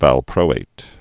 (văl-prōāt)